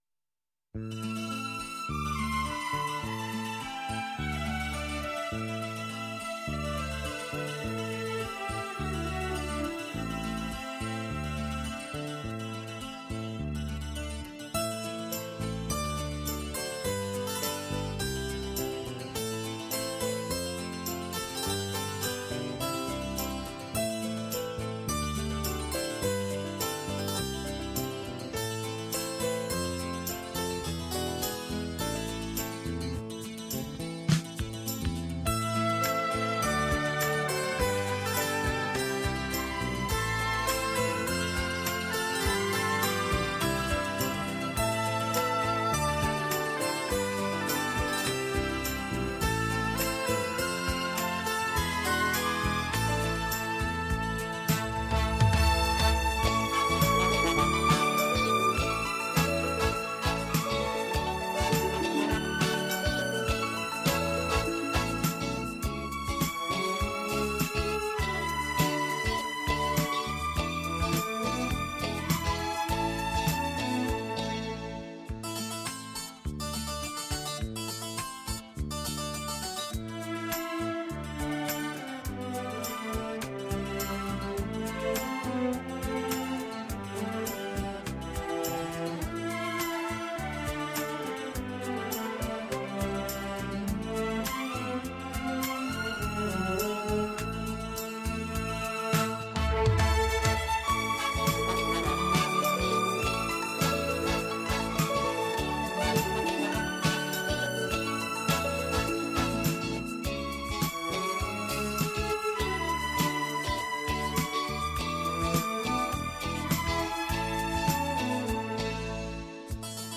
他的音乐华丽而不滟俗，浪漫而不轻浮，粗旷之中带着细腻，热情之中蕴藏着宁静。